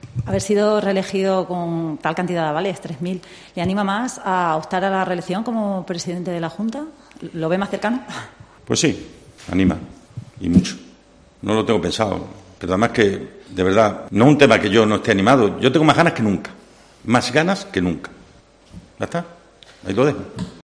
Vara ha realizado estas declaraciones en rueda de prensa este lunes en Mérida después de que la Comisión Regional de Ética le proclamase este pasado domingo como secretario general del PSOE de Extremadura dentro del proceso iniciado del 13º Congreso Regional.